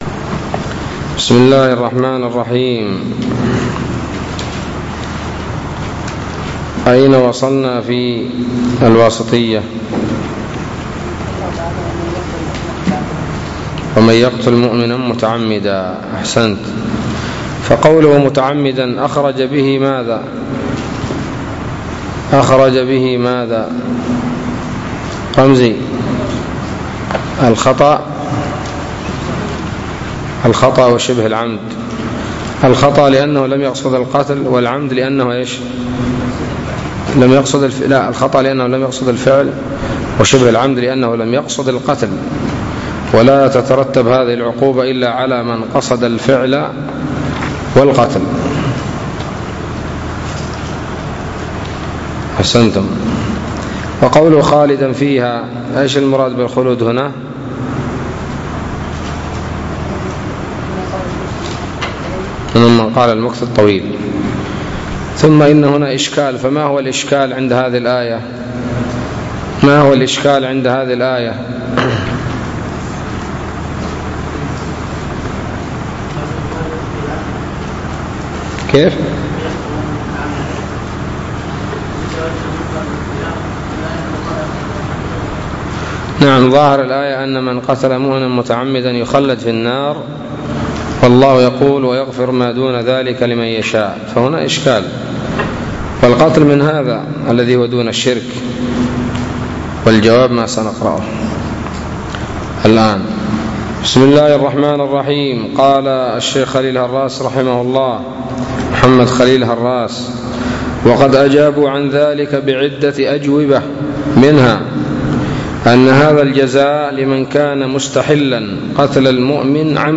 الدرس الخامس والخمسون من شرح العقيدة الواسطية